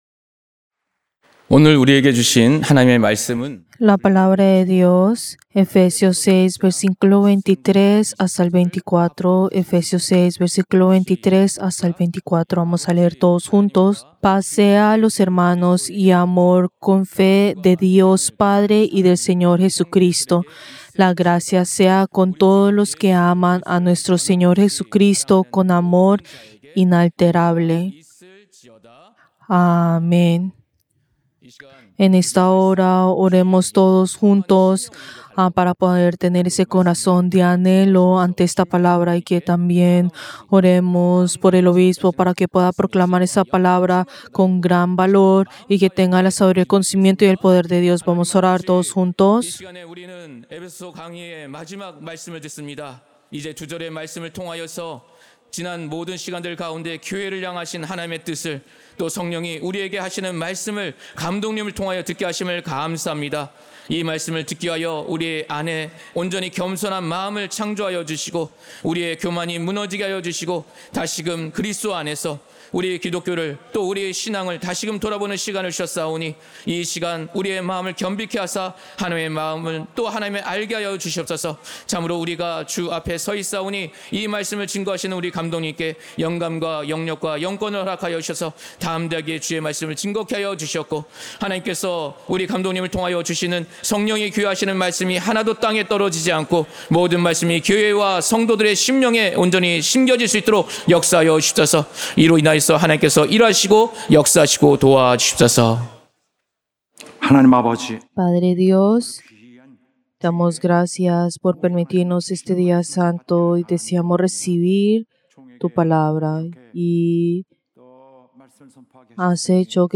Servicio del Día del Señor del 24 de agosto del 2025